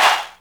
Vox
ReddChant.wav